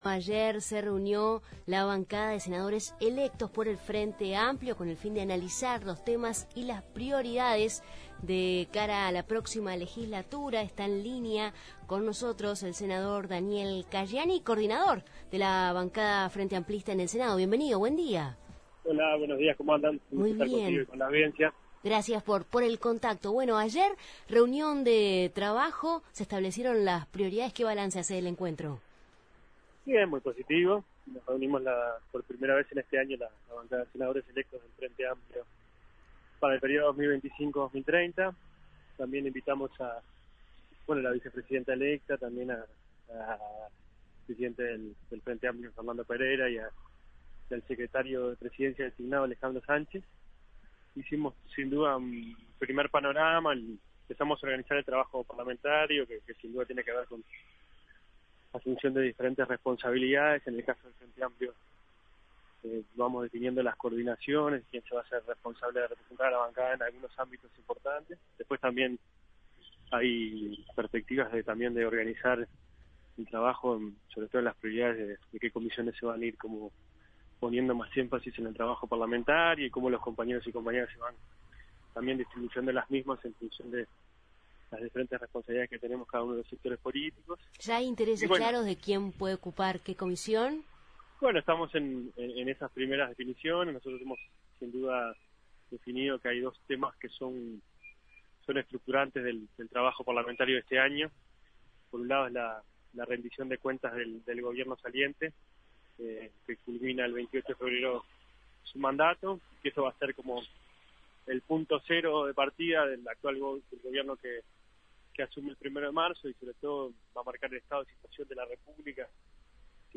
En Justos y pecadores entrevistamos a Daniel Caggiani, coordinador de la bancada del Frente Amplio en el Senado